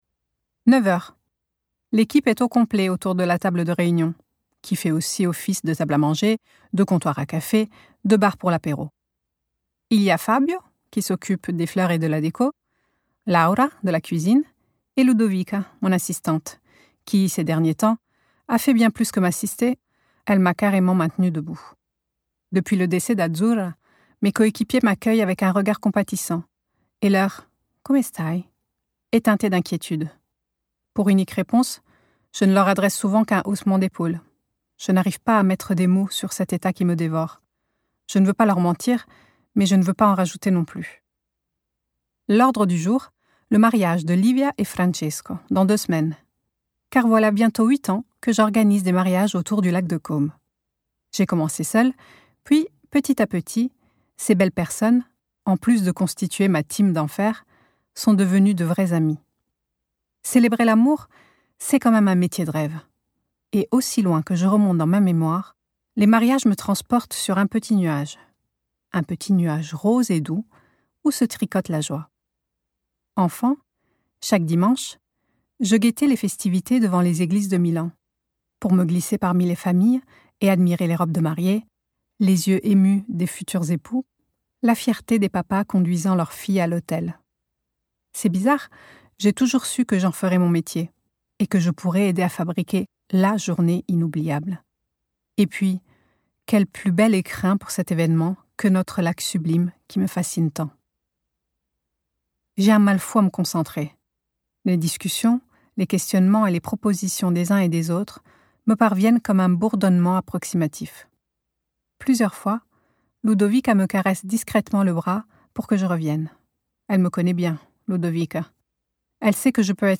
Roman lu par l'autrice et comprenant un chapitre bonus !Valentina organise des mariages sur le lac de Côme.